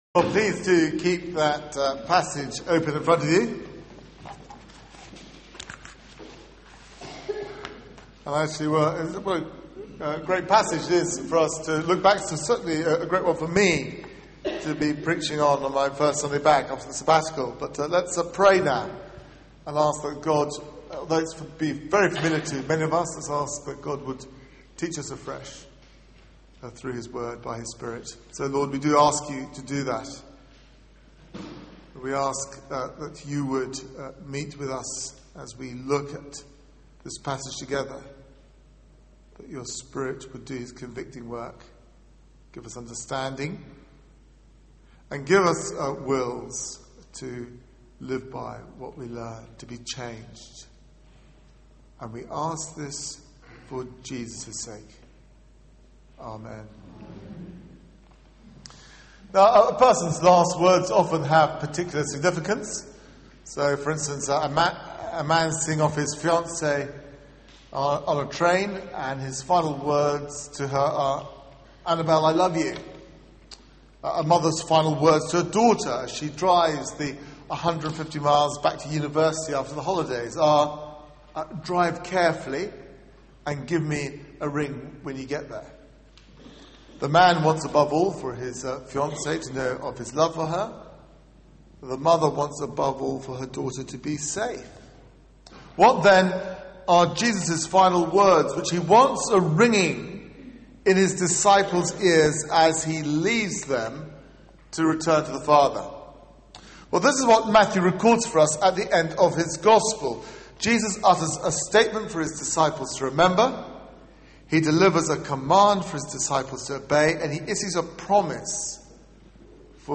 Media for 9:15am Service on Sun 01st May 2011
Series: The Week that Changed the World Theme: The commission Sermon